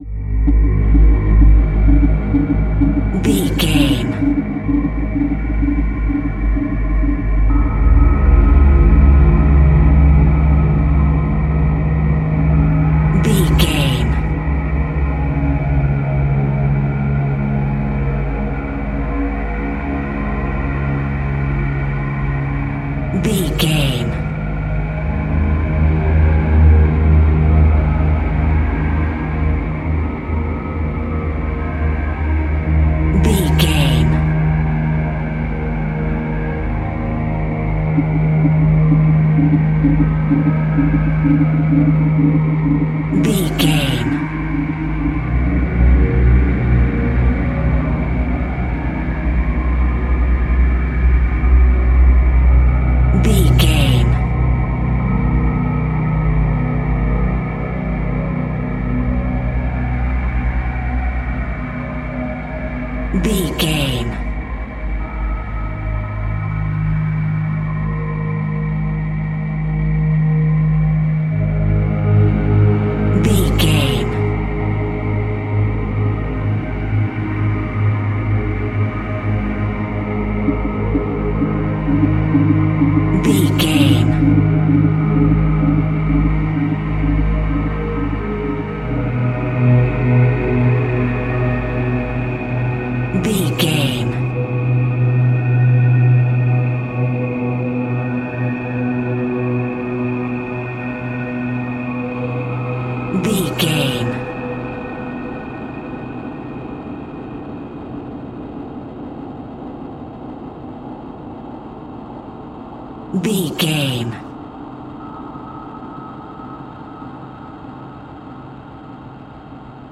Atonal
scary
ominous
dark
suspense
haunting
eerie
synthesizer
keyboards
ambience
pads
eletronic